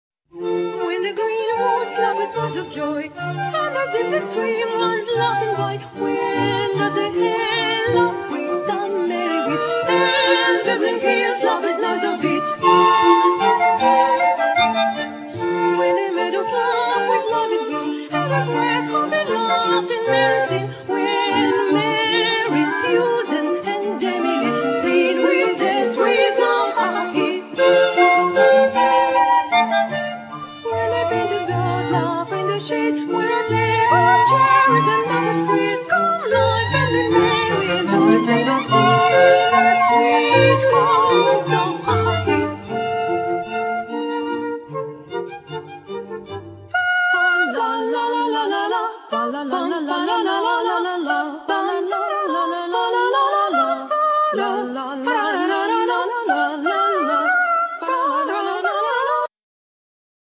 Voice
Flute
Clarinet
Keyboards
Violin
Cello